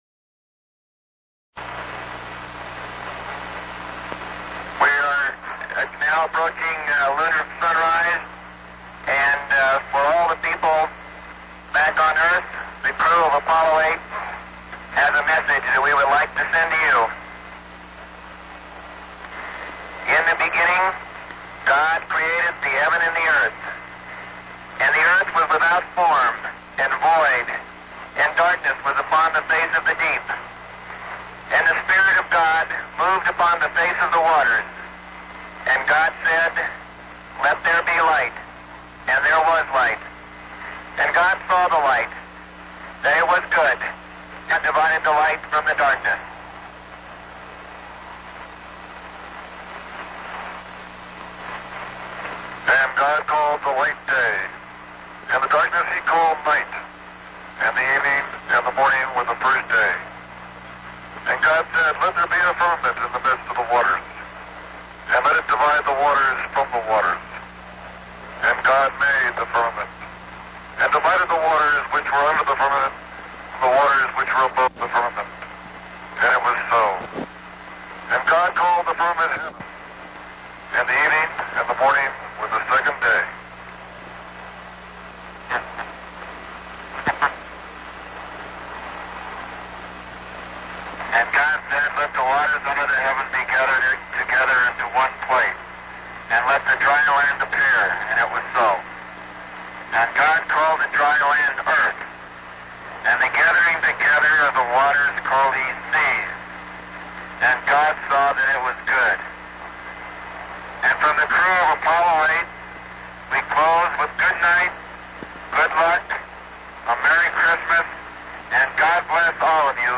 On Christmas Eve, in a special television broadcast, the crew offered the world a special present.
Apollo-8s-Christmas-Eve-1968-Message.mp3